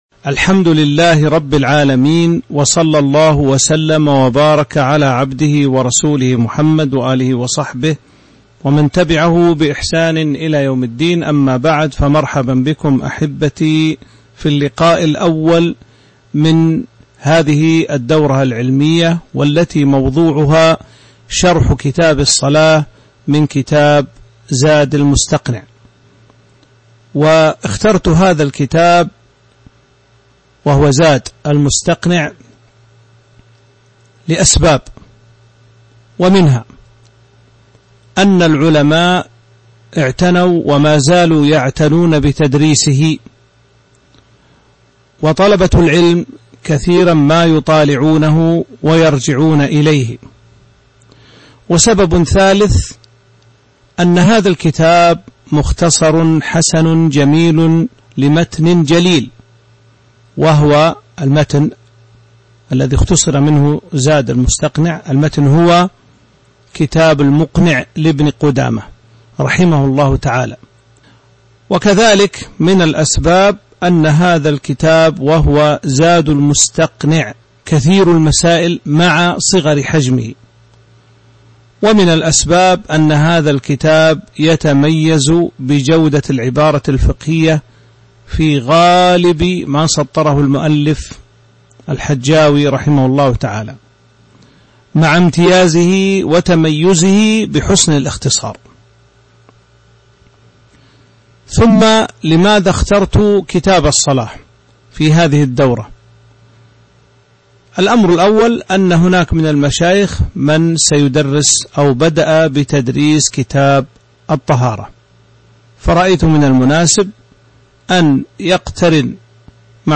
تاريخ النشر ٢٠ ذو الحجة ١٤٤٢ هـ المكان: المسجد النبوي الشيخ